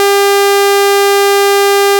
sawtooth wave:
sound-sawtooth.wav